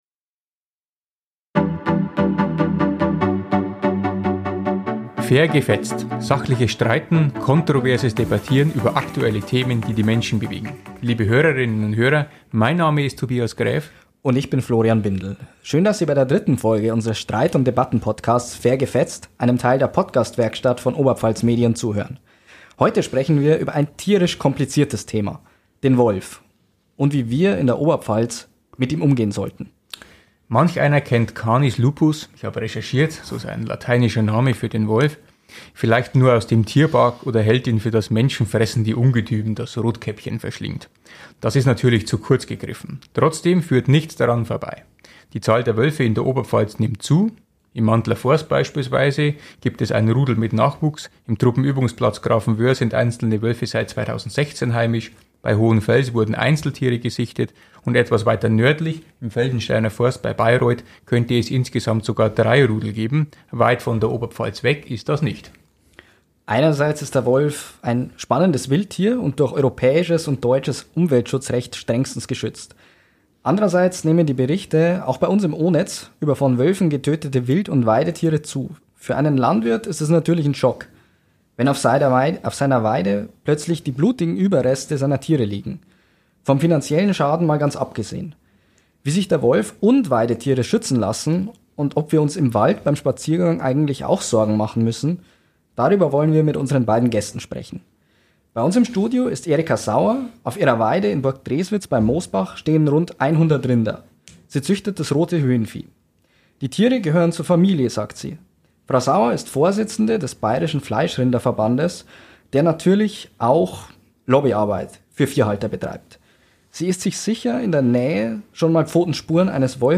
Eine Tierhalterin und ein Naturschützer streiten über den richtigen Umgang mit dem Wolf.